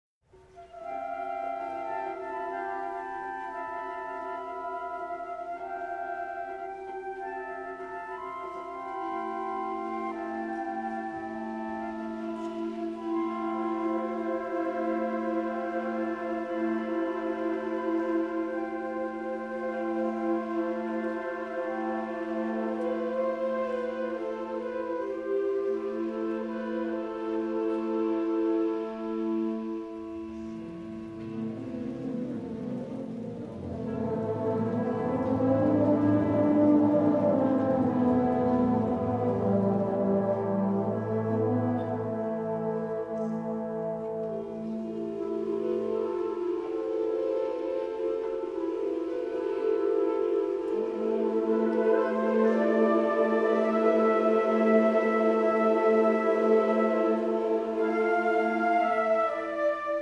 perform here live in their send off concert at home